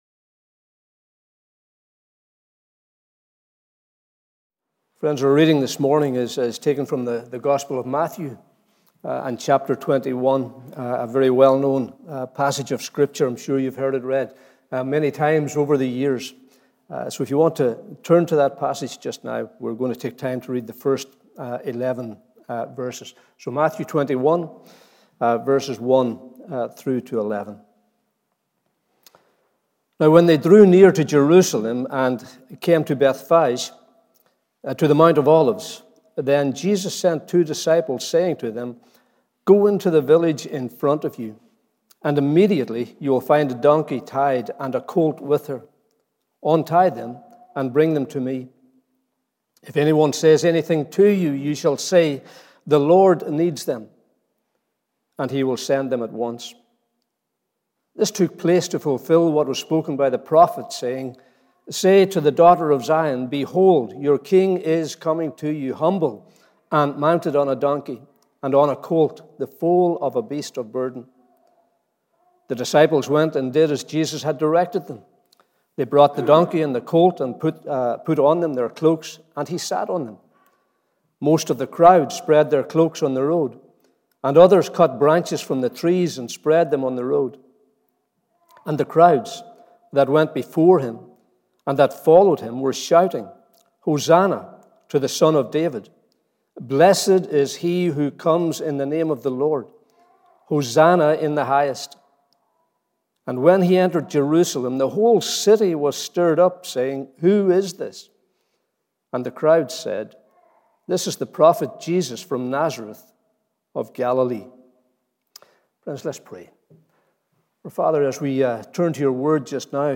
Morning Service 10th April 2022